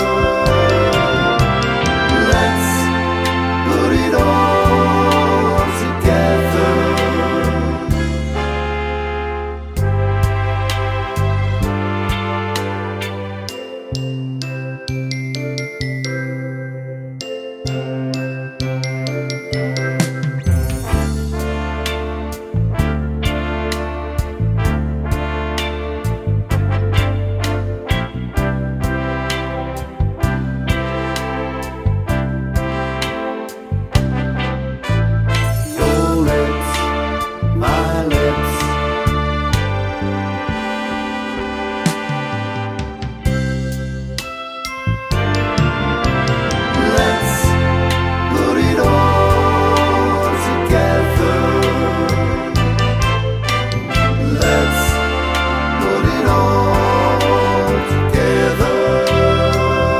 no Backing Vocals Soul / Motown 3:30 Buy £1.50